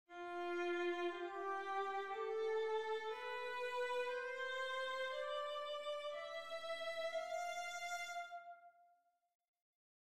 The Lydian mode runs from F to F giving us a TTTSTTS template. It starts off like a major scale but has added bite (rather like being surprised by a tangy cheese or a squirt of lemon juice) in its sharpened fourth note (B-natural – the scale of F major has a B-flat).
Lydian
lydian.mp3